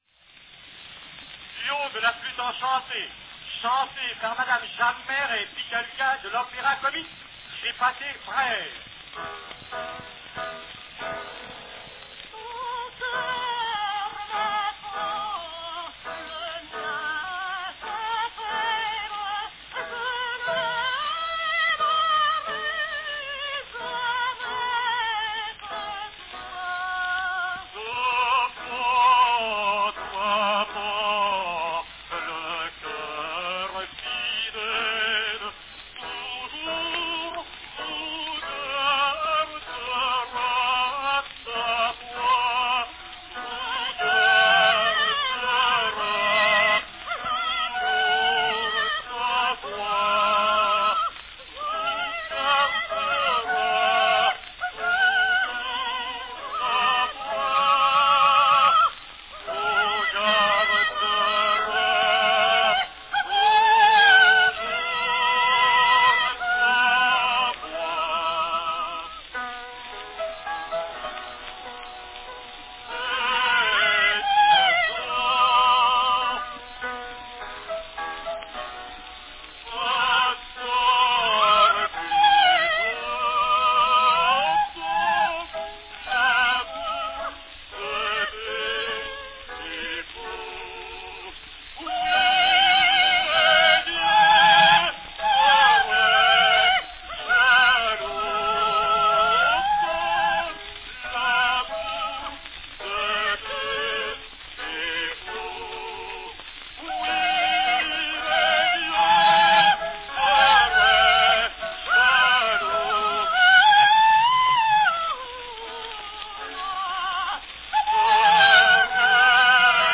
a duet
Category Duet
This recording is on a Pathé 3½" 'salon' size cylinder.